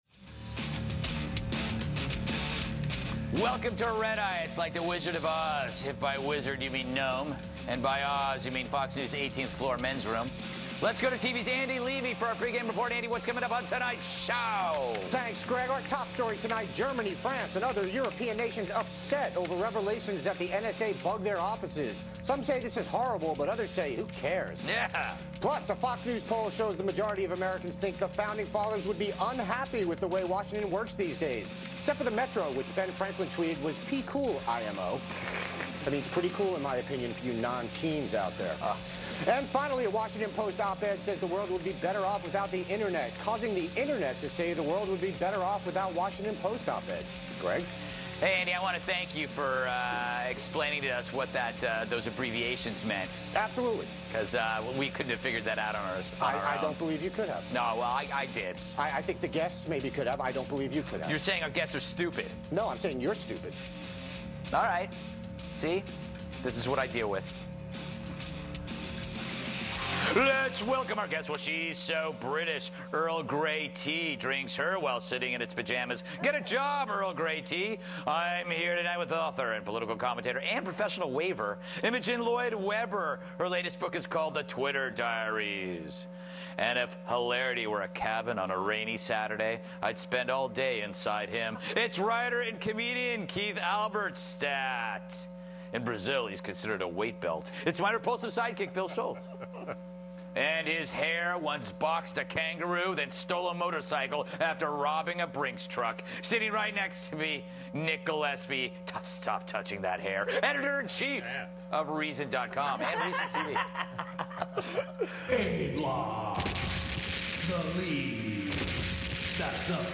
On July 2, Nick Gillespie appeared on Fox News' Red Eye with Greg Gutfeld to discuss the EU outcry to allegations of NSA spying, Jennifer Lopez's concert for the dictator of Turkmenistan, a teenager in jail over a Facebook comment, an ex-vegan shaming website, and more!